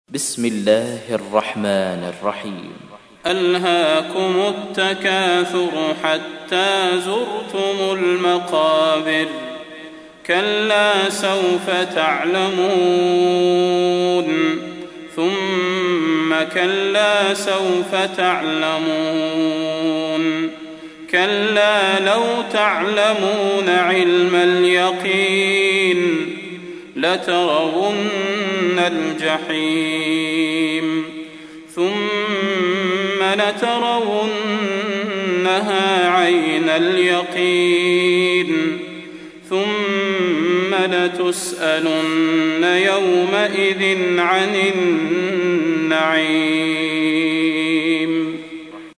تحميل : 102. سورة التكاثر / القارئ صلاح البدير / القرآن الكريم / موقع يا حسين